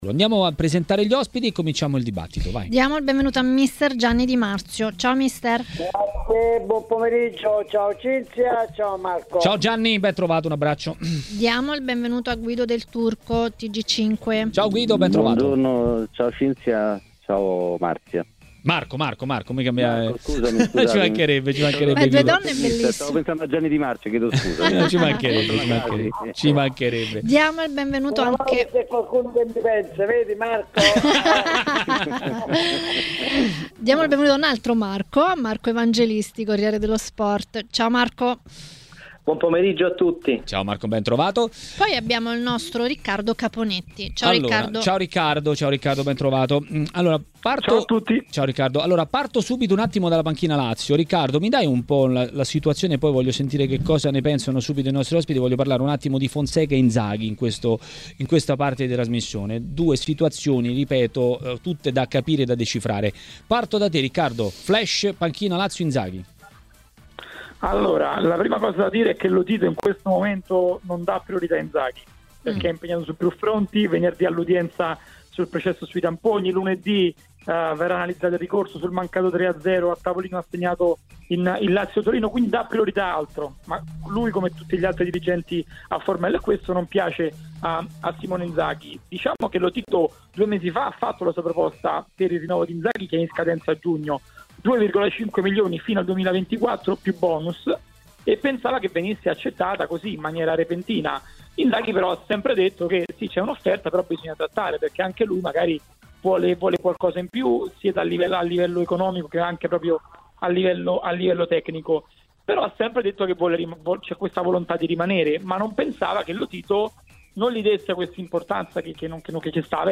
Per parlare dei casi del giorno a Maracanà, nel pomeriggio di TMW Radio, è intervenuto mister Gianni Di Marzio.